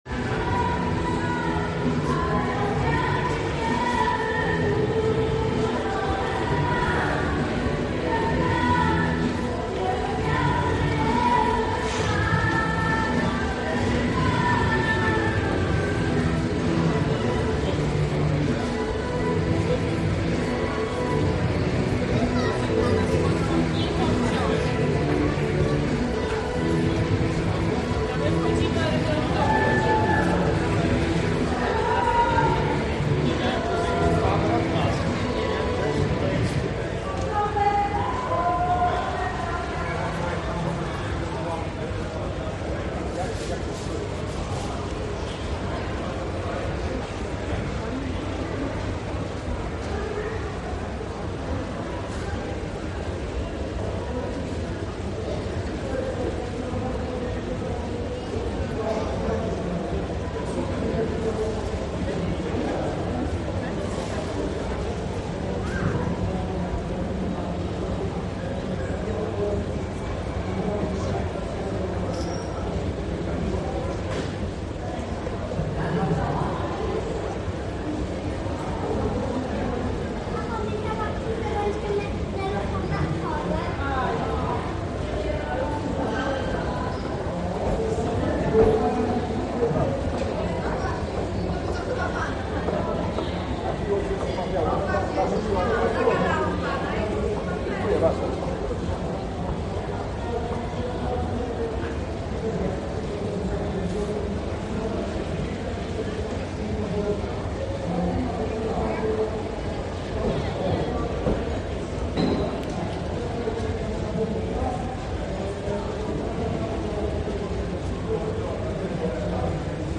Zarówno sam koncert, jak i rozmowy prowadzone przy użyciu mikrofonów generowały znaczny hałas, który był słyszalny nie tylko w bezpośrednim sąsiedztwie wydarzenia (przy głównym wejściu do galerii), ale również w innych jej częściach: przy schodach ruchomych na niższym poziomie oraz w strefie gastronomicznej na drugim piętrze[4].
Nagranie nr 5, Pasaż Grunwaldzki, Wrocław, Schody ruchome przy punkcie pocztowym, 26 listopada 2024, godz. 16:18, czas trwania: 2 min. 27 s.